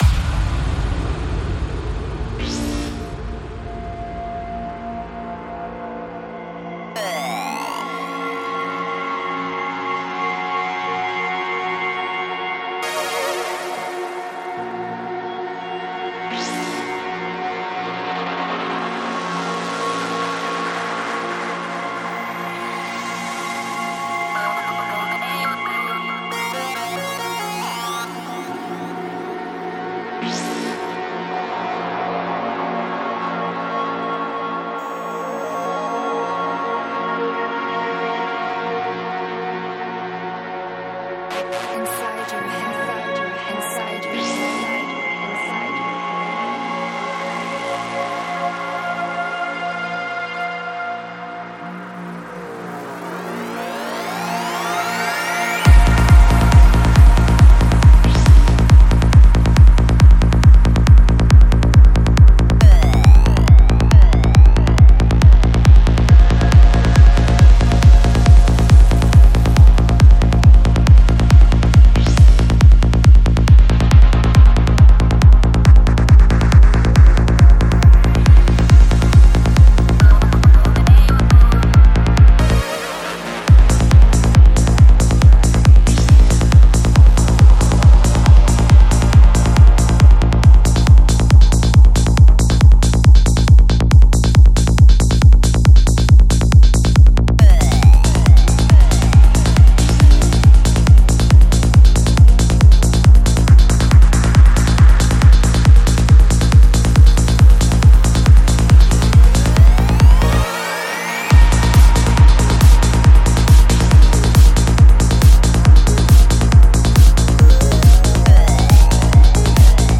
Psy-Trance